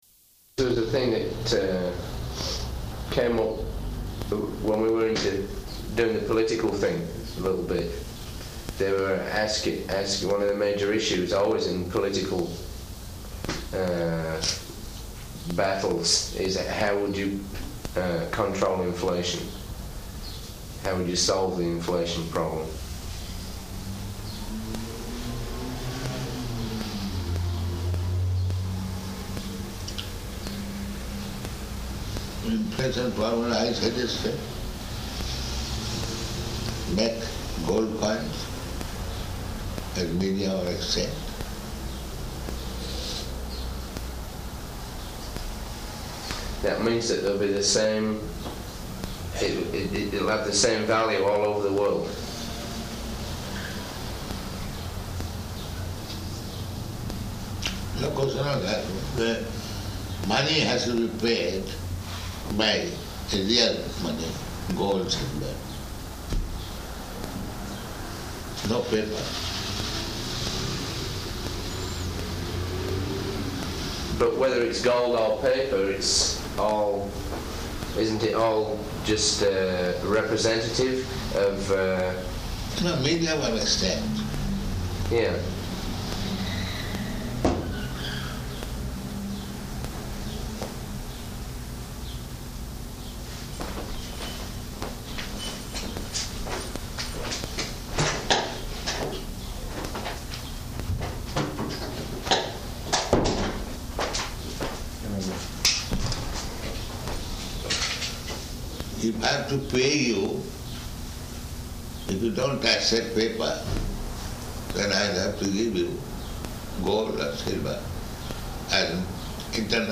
Room Conversation
Type: Conversation
Location: Toronto